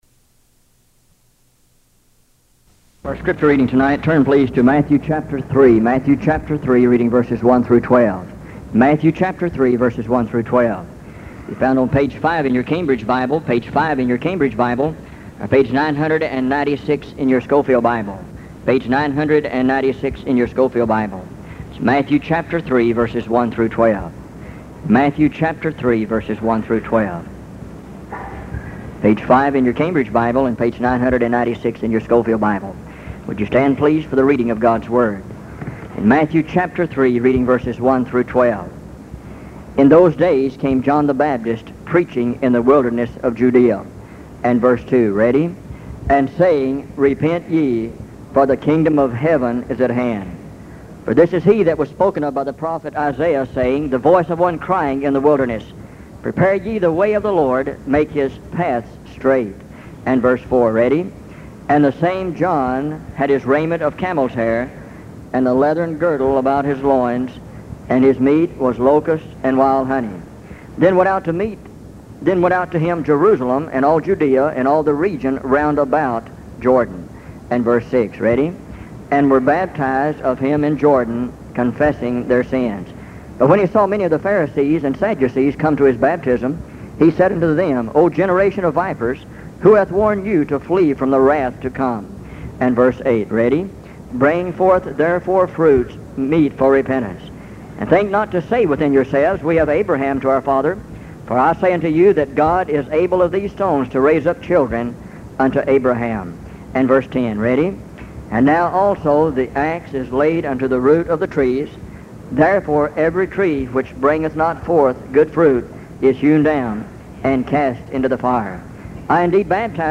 Sermon Category: Holiness